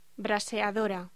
Locución: Braseadora